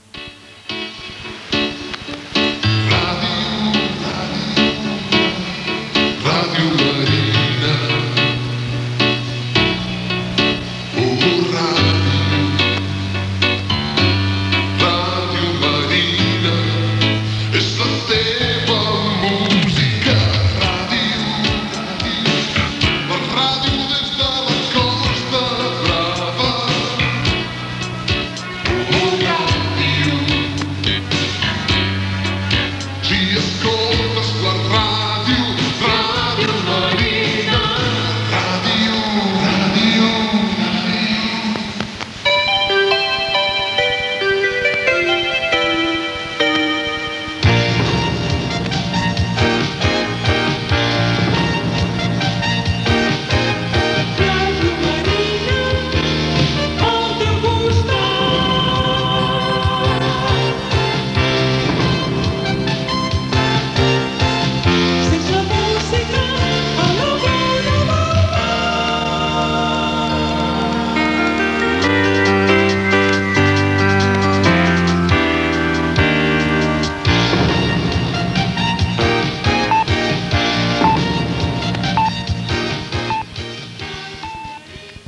Indicatiu de l'emissora i jingle